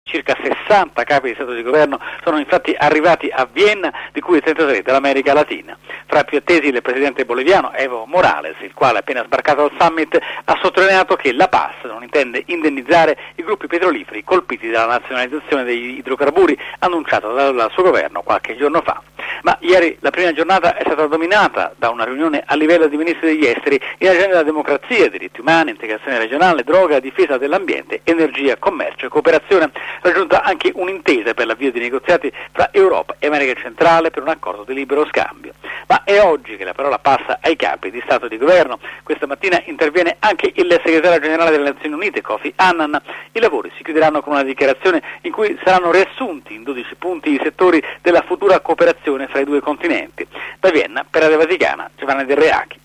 Da Vienna